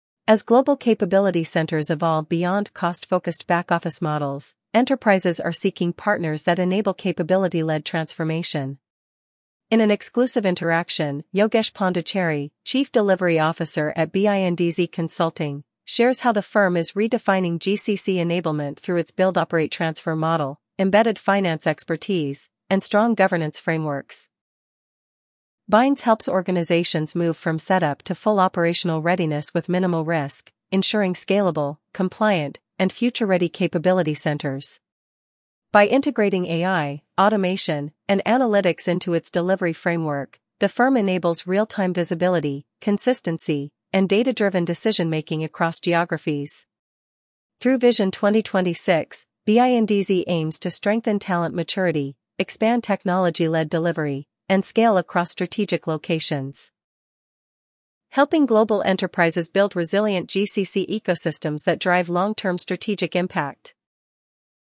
Redefining GCCs as Strategic Capability Hubs: An Interaction with BINDZ Consulting